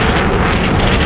rocket_engine_start_idle.wav